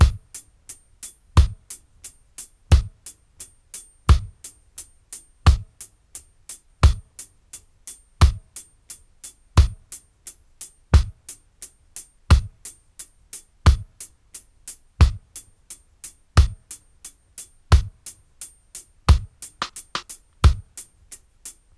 88-DRY-02.wav